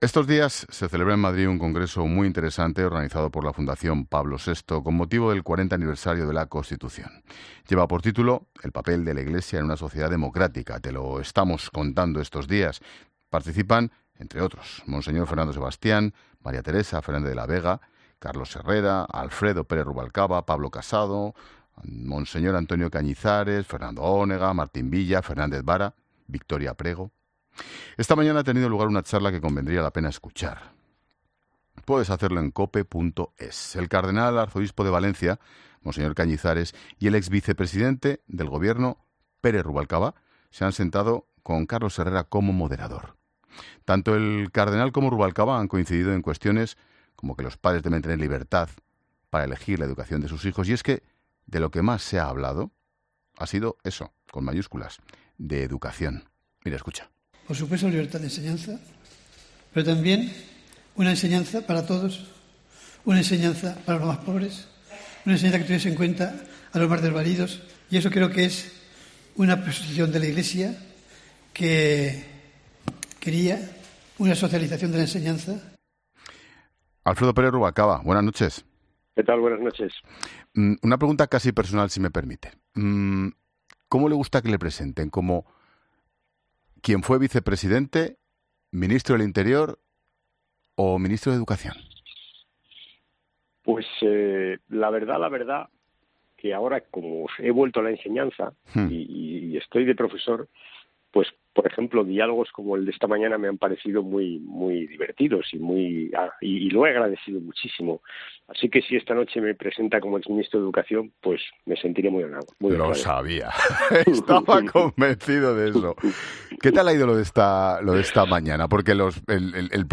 Por los micrófonos de 'La Linterna' ha pasado el ex vicepresidente del Gobierno para hacer un balance de estas charlas.